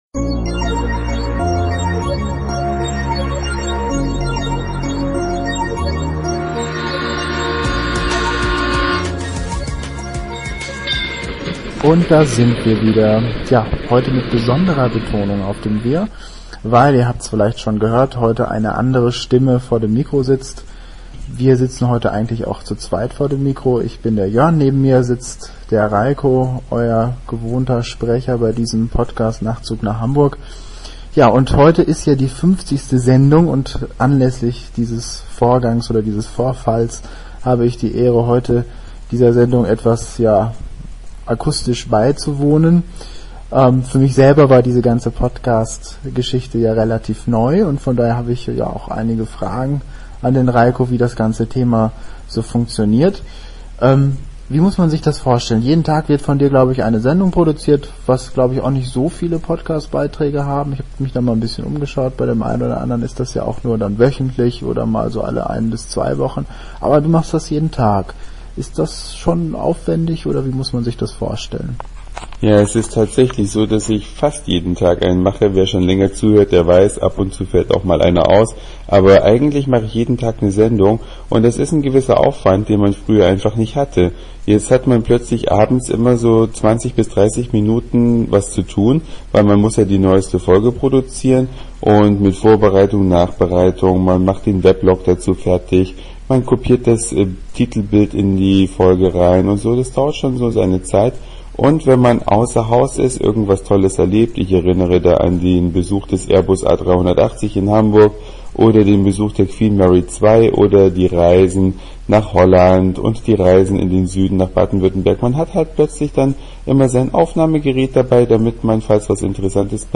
Die 50. Sendung beginnt mit einem Interview.
Die heutige Sendung kommt aus Frankfurt, ab Montag wird wieder aus Hamburg gesendet.